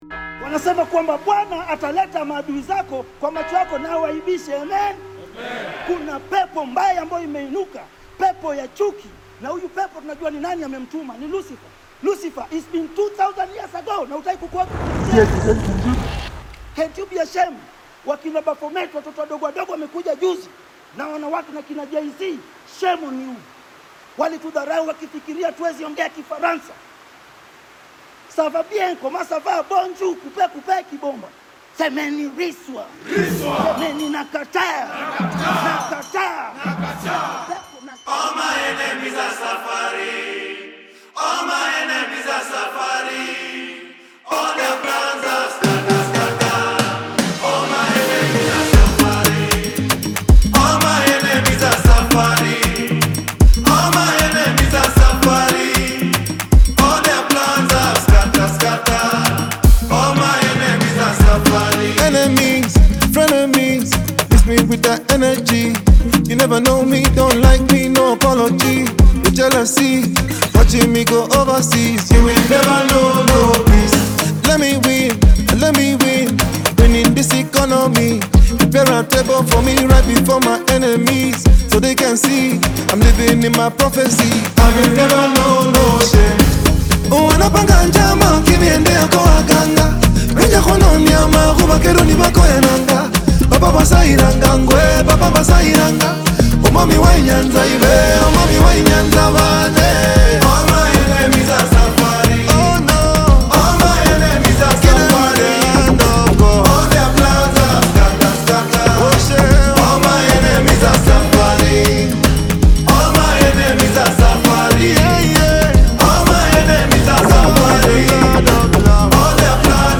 RNB
• Genre: Kenyan